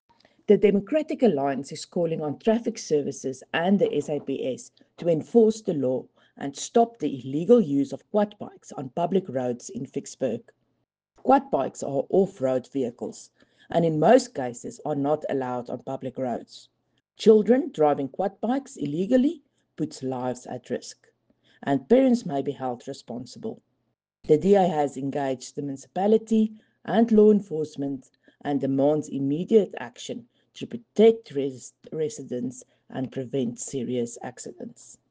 Afrikaans soundbites by Cllr Riëtte Dell and